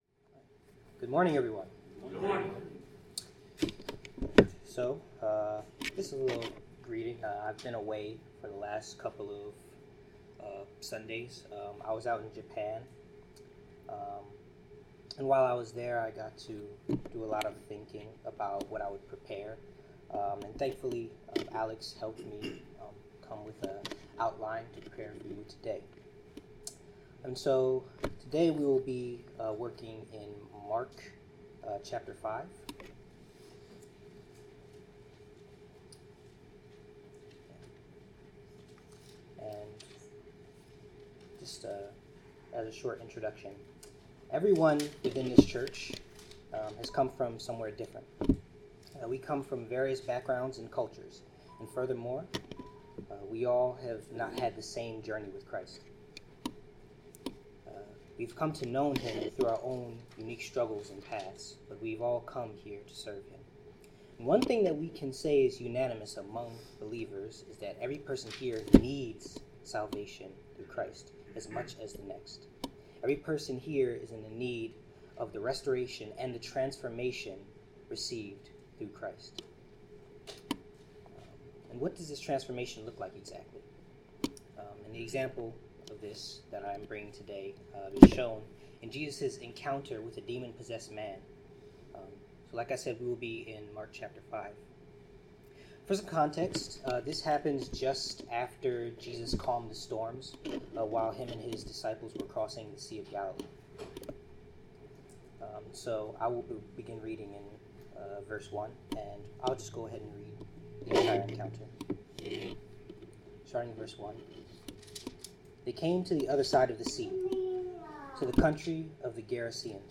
Passage: Mark 5:1-20 Service Type: Sermon